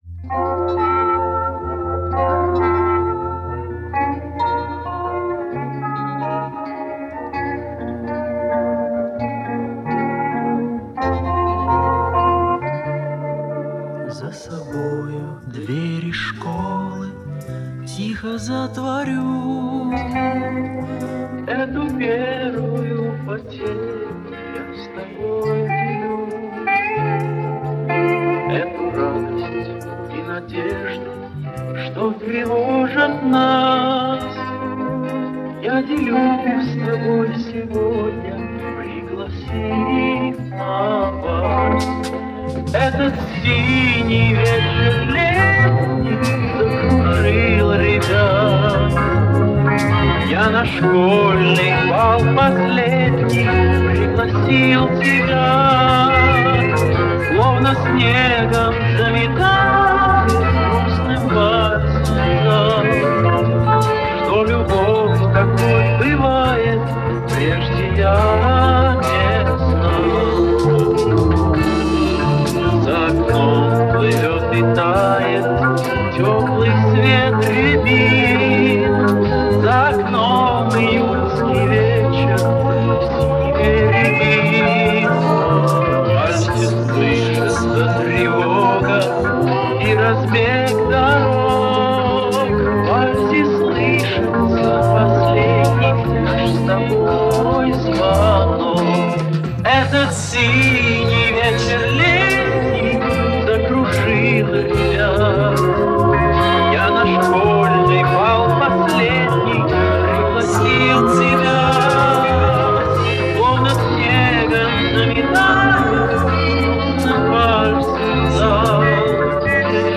(radio) (remastering - 11.03.2024)